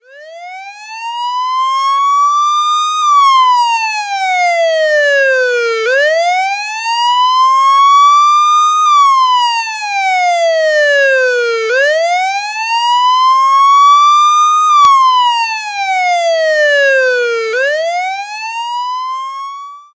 Catégorie Effets Sonores